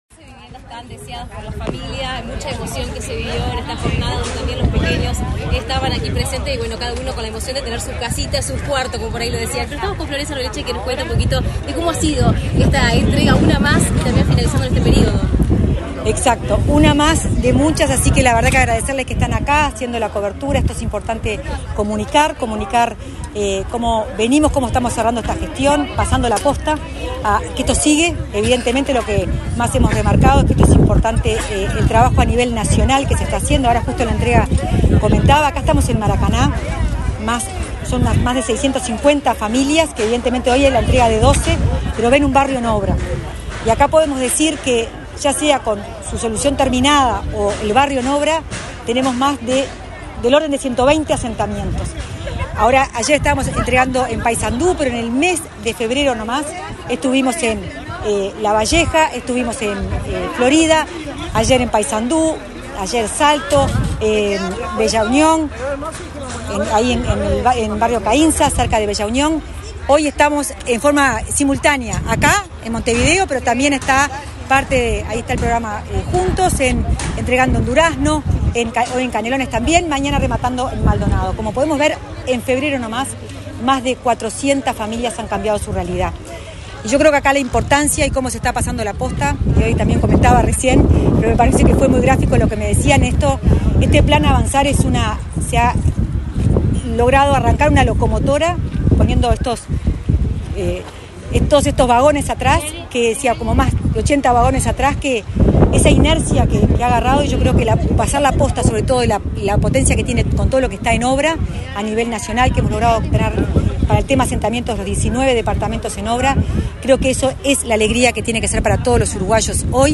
Palabras de la directora nacional de Integración Social y Urbana, Florencia Arbeleche
Palabras de la directora nacional de Integración Social y Urbana, Florencia Arbeleche 27/02/2025 Compartir Facebook X Copiar enlace WhatsApp LinkedIn El Ministerio de Vivienda y Ordenamiento Territorial entregó, este 27 de febrero, 12 viviendas en el barrio Maracaná Sur de Montevideo, en el marco del plan Avanzar. En el evento disertó la directora nacional de Integración Social y Urbana de la cartera, Florencia Arbeleche.